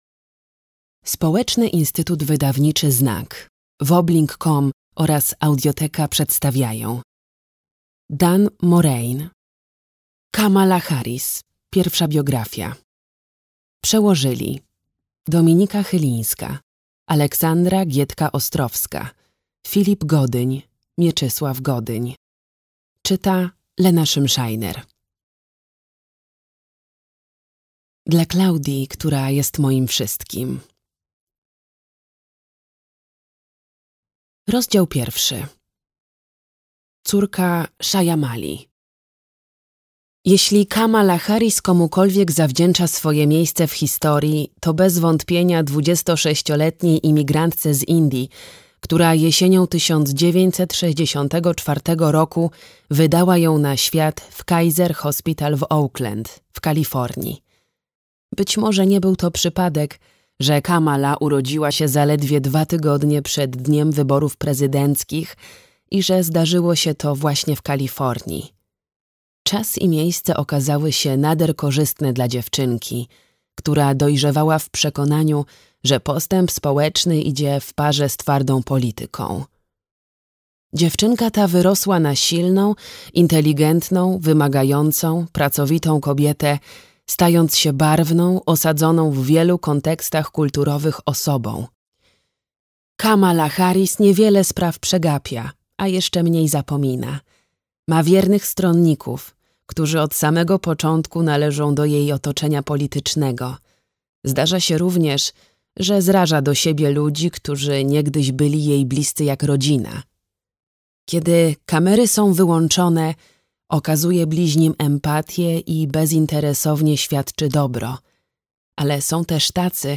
Kamala Harris. Pierwsza biografia - Morain Dan - audiobook